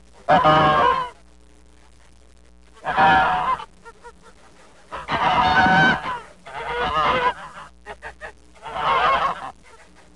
Cluk Cluk Sound Effect
Download a high-quality cluk cluk sound effect.
cluk-cluk.mp3